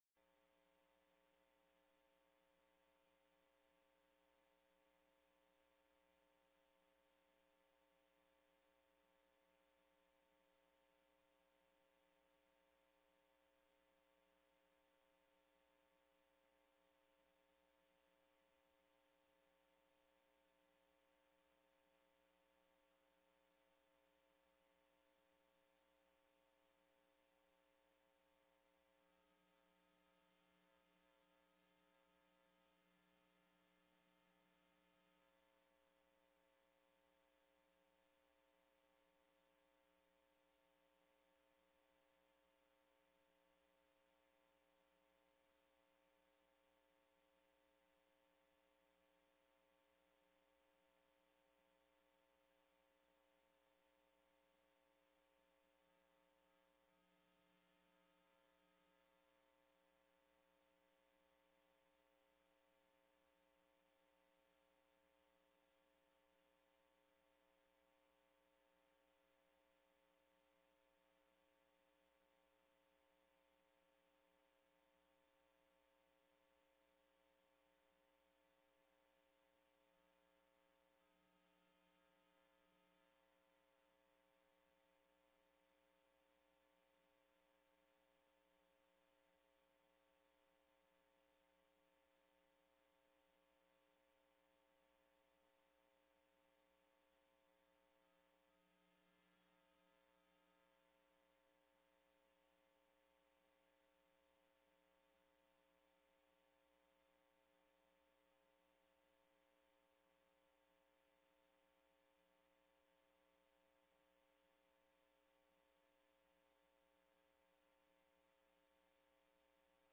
تاريخ النشر ١٩ محرم ١٤٣٨ هـ المكان: المسجد النبوي الشيخ